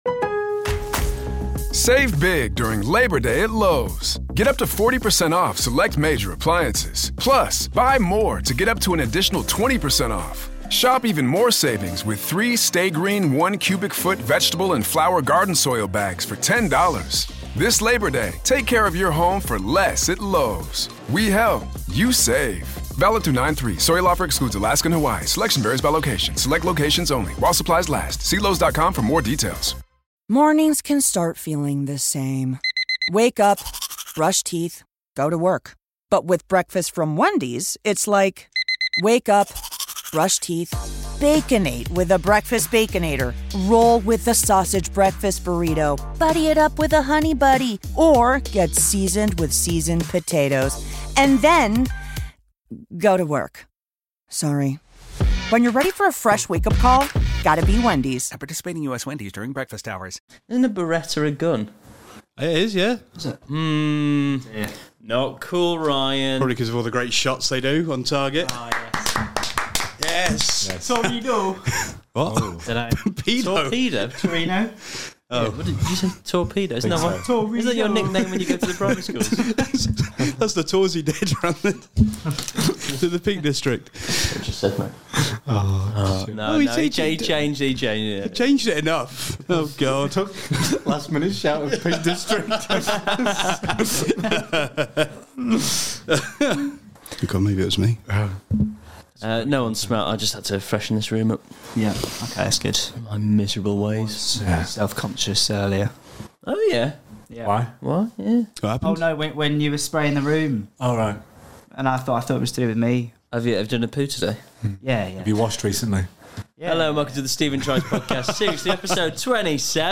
Comedy Interviews, Comedy